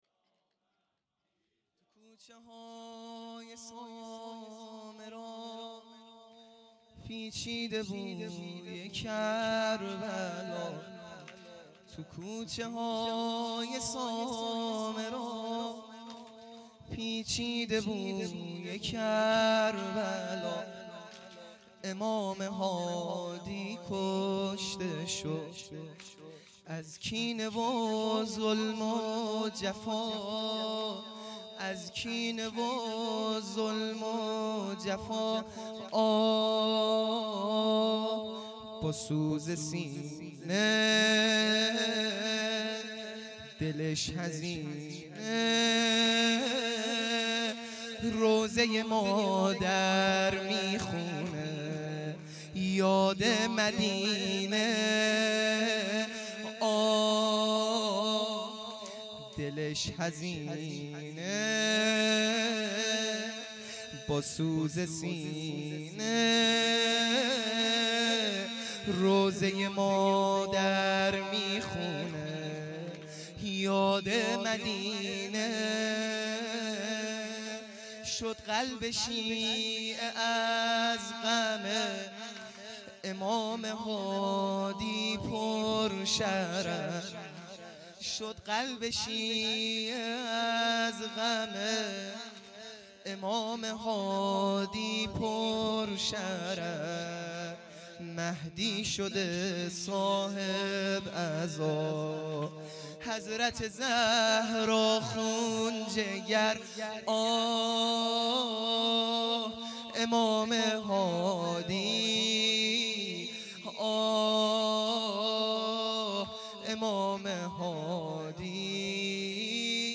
سنگین | تو کوچه های سامرا